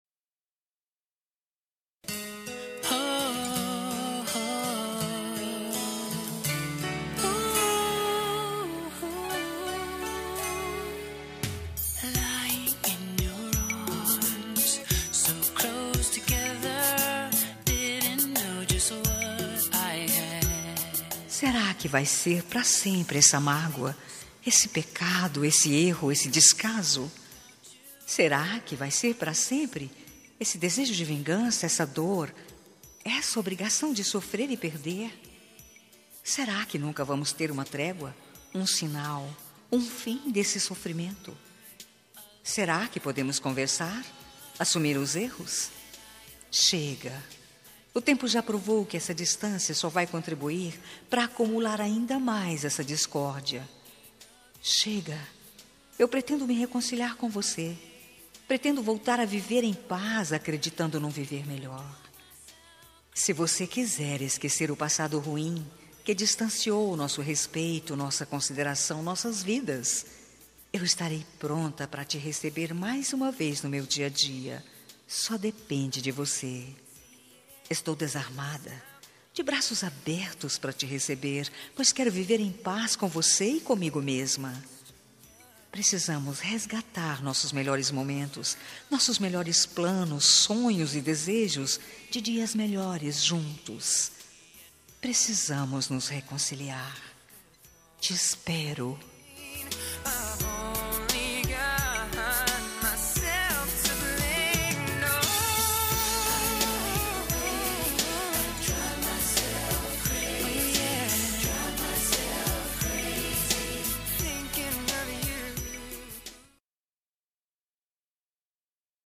Telemensagem de Reconciliação Romântica – Voz Feminina – Cód: 901